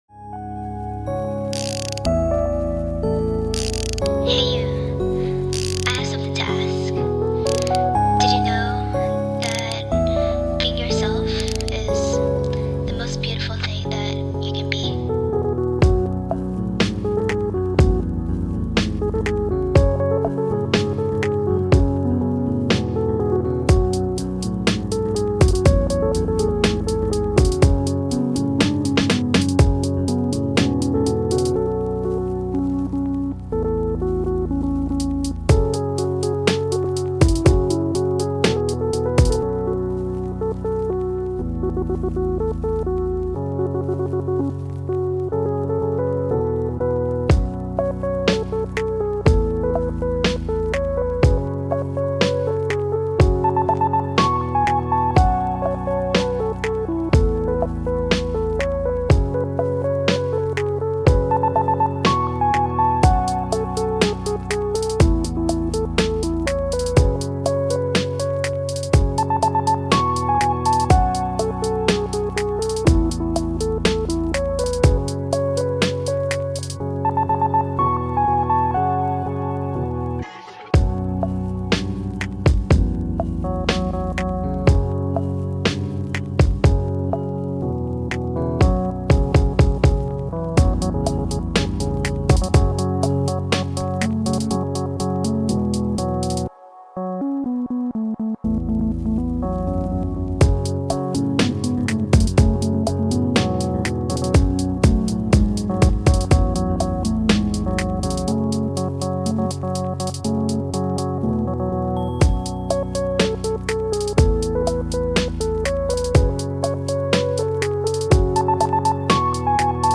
a chillax hip hop lofi instrumental